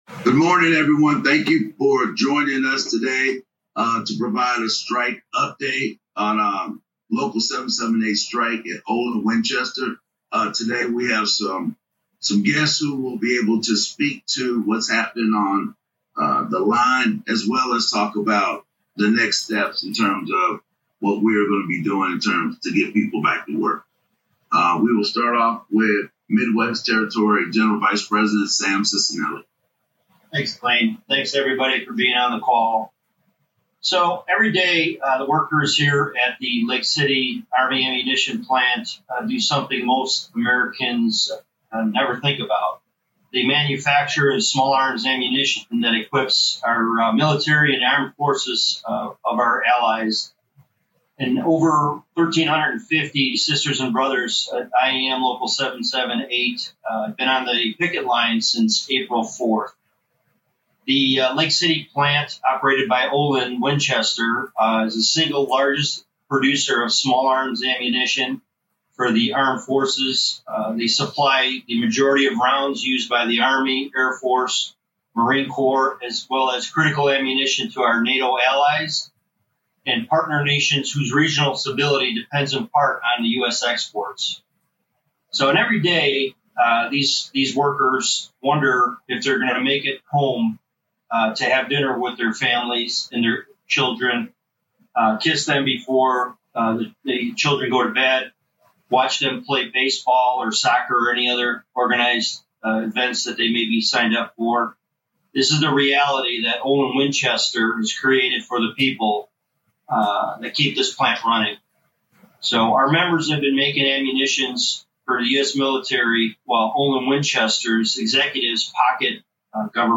Listen to the full audio of the Roundtable Discussion